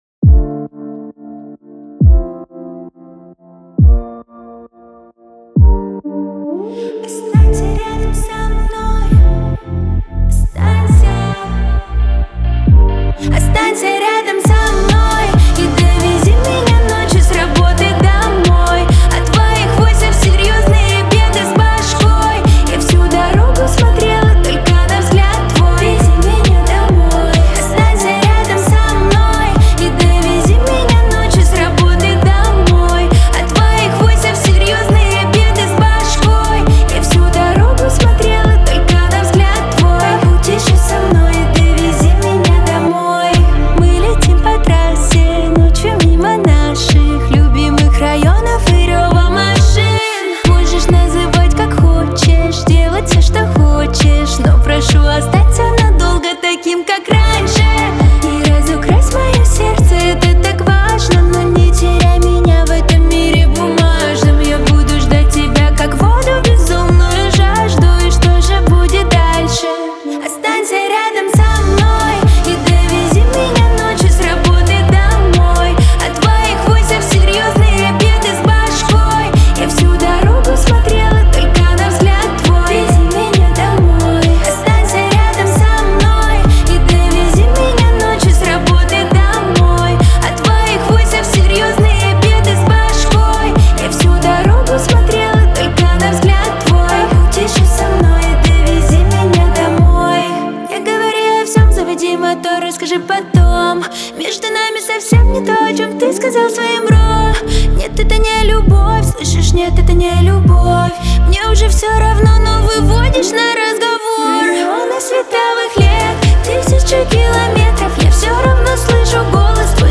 Жанр: Русские народные песни